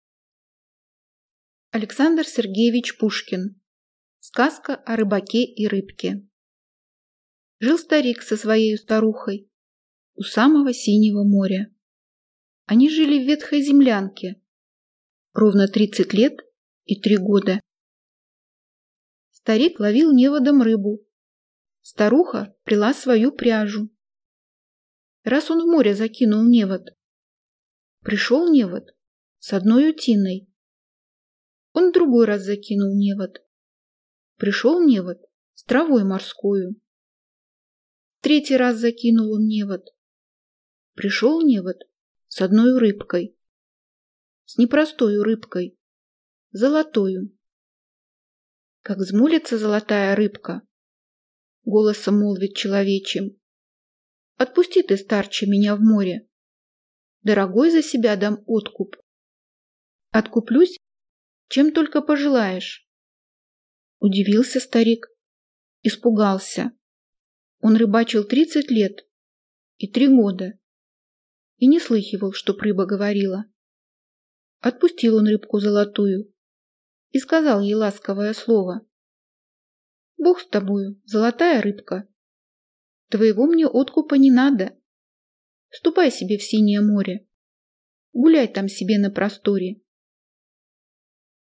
Аудиокнига Сказка о рыбаке и рыбке | Библиотека аудиокниг